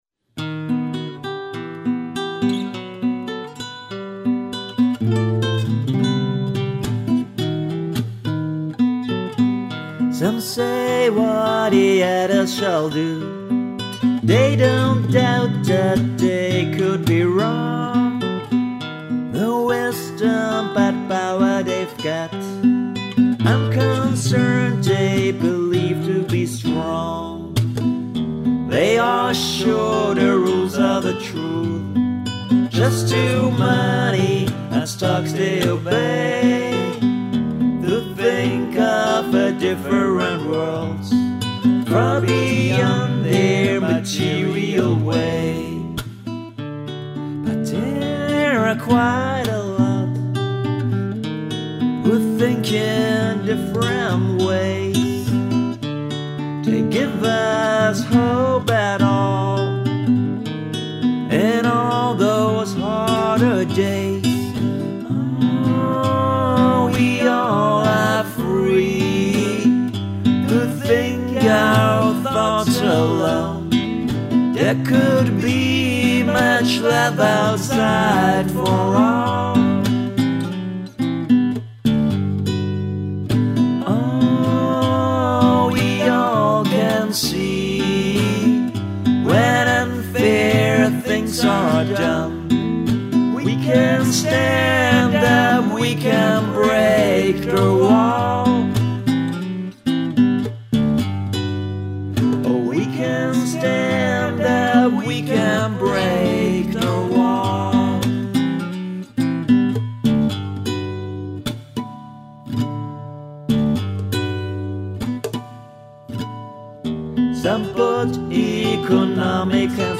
vocals, guitar